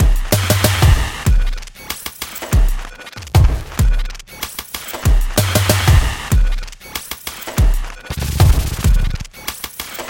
描述：一个带有轻微偏色的节奏的环境节拍。
Tag: 95 bpm Weird Loops Drum Loops 1.70 MB wav Key : Unknown